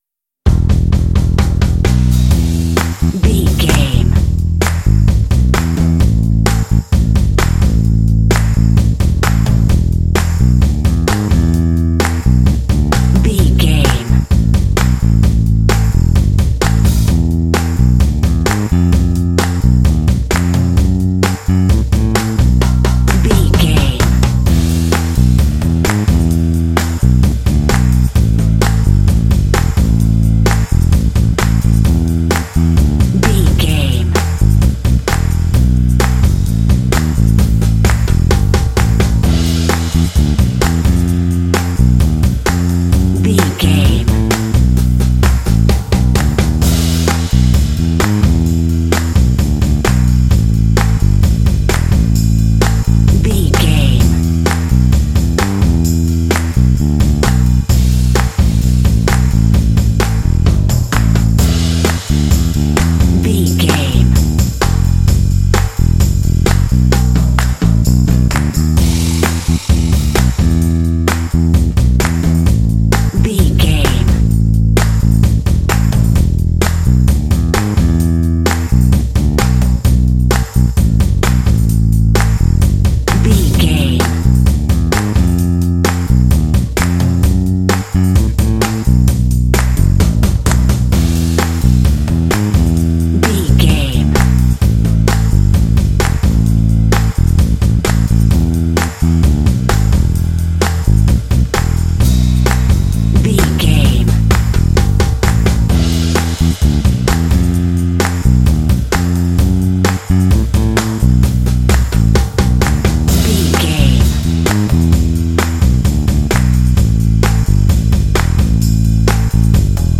This groovy track is ideal for action and sports games.
Aeolian/Minor
lively
energetic
groovy
drums
bass guitar